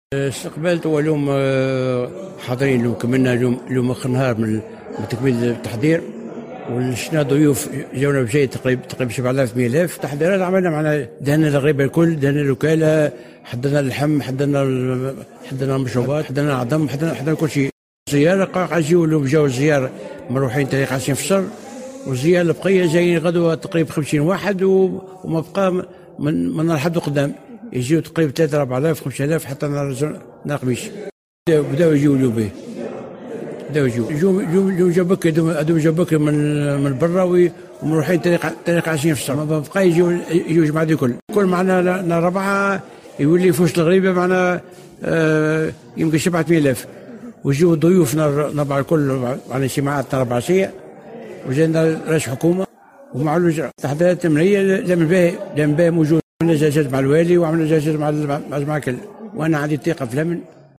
تصريح لمراسلة الجوهرة اف ام